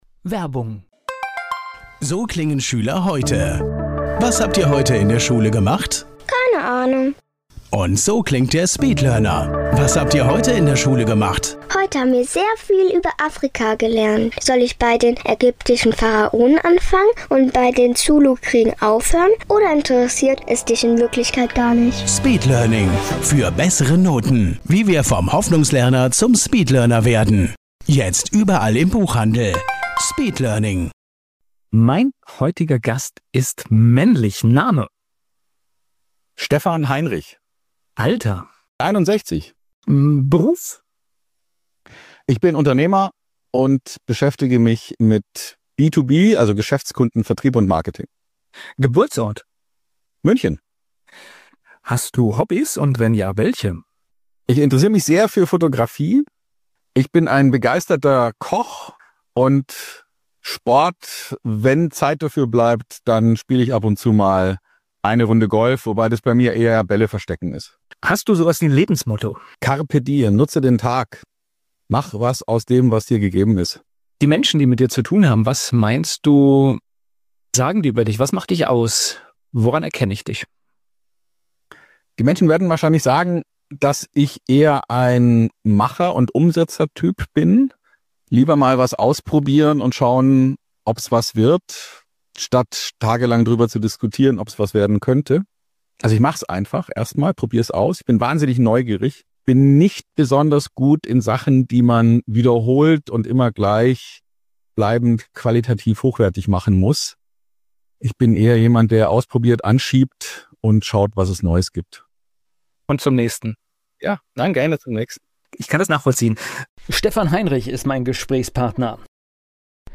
im Gespräch ~ ANTENNE MAINZ Sonntagstalk